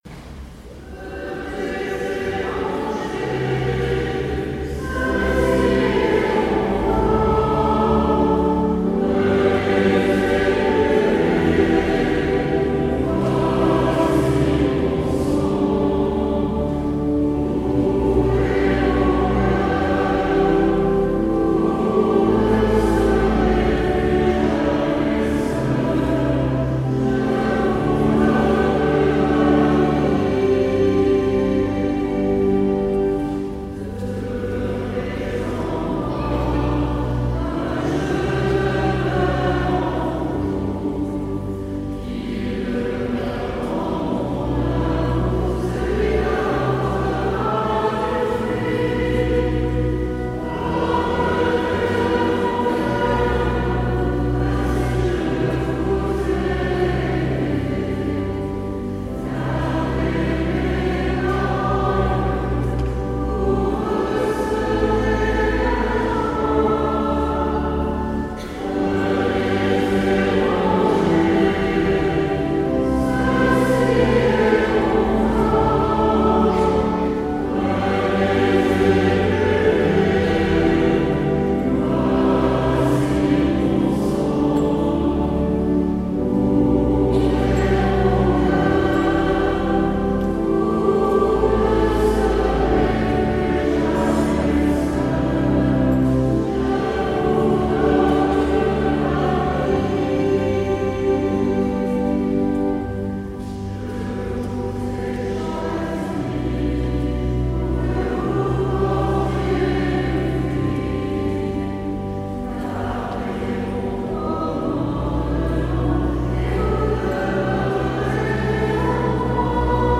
Communion
Tutti